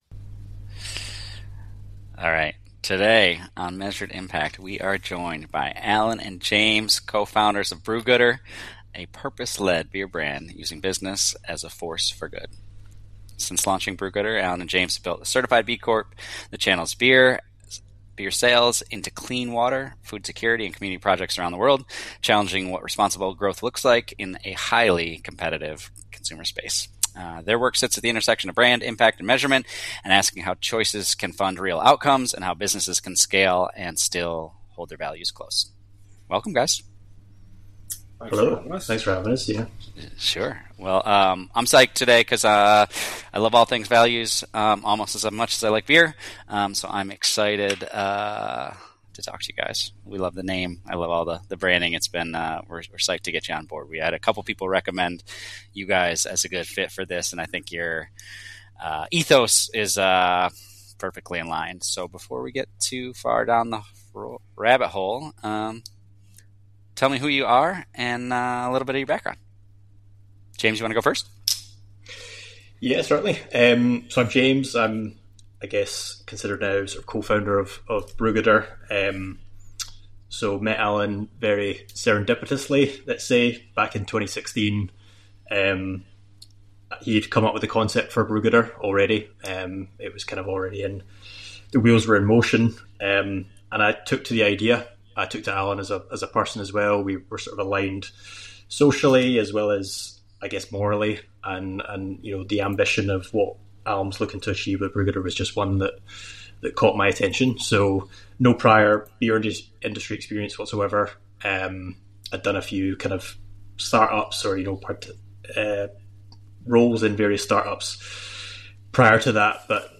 We capture their candid stories of how activism, brand building, and revenue growth can work in tandem. Each episode features sharp conversations with changemakers proving that doing good and doing well aren’t mutually exclusive.